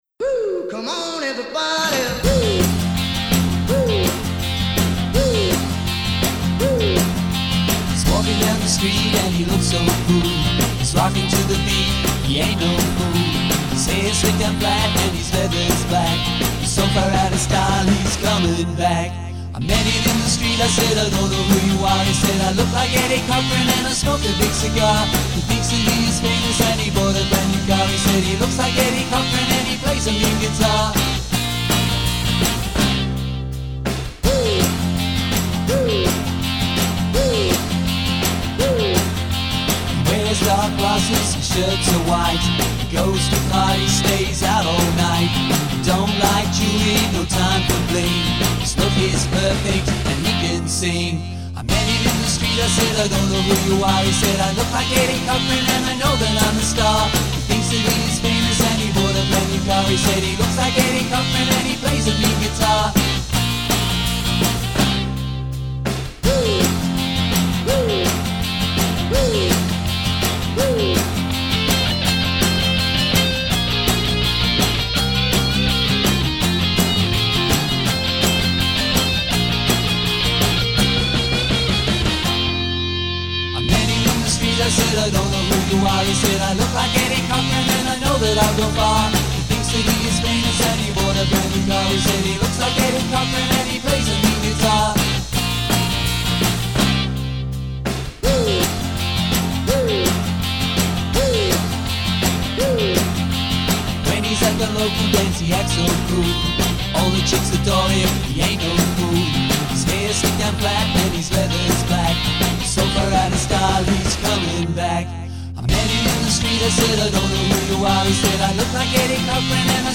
retro 60s garage pop
containing 14 original handcrafted catchy retro pop tunes .